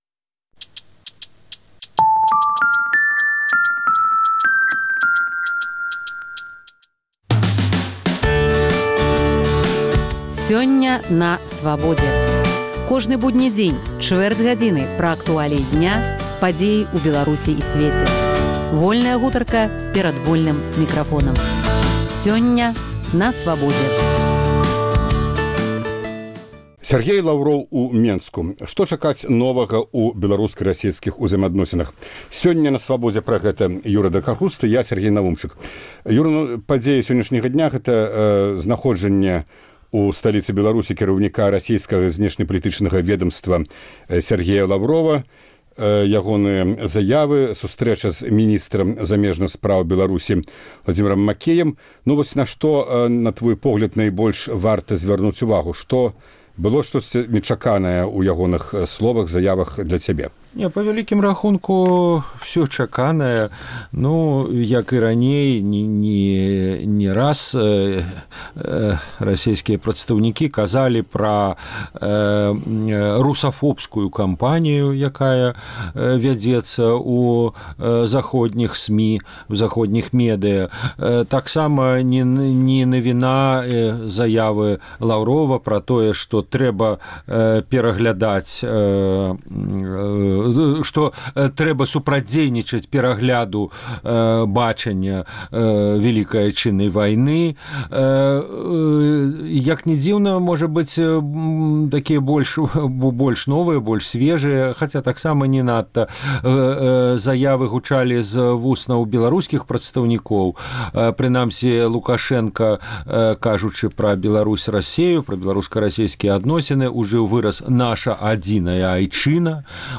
Абмяркоўваюць